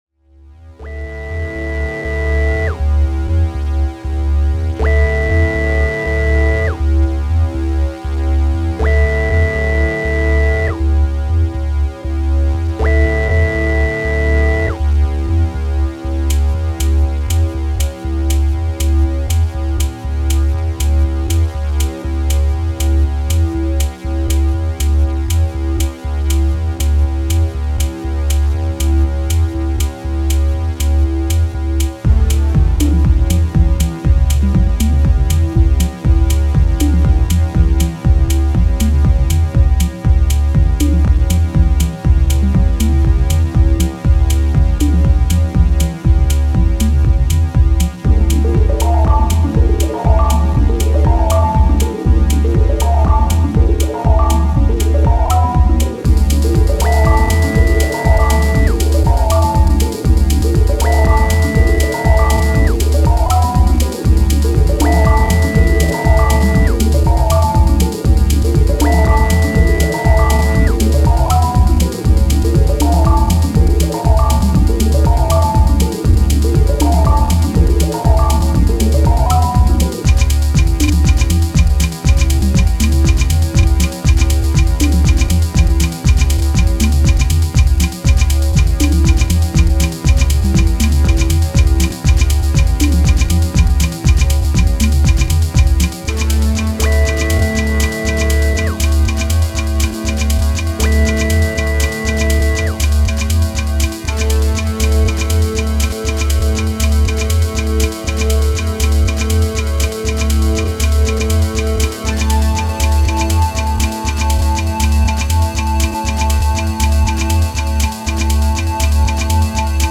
モダンでミニマルなハウス・グルーヴをストイックに追い求めていった、職人的な気質が光る一枚に仕上がっています！
出だしから不穏な空気で始まり最後まで抜け感を感じさせることなく終わっていくb2！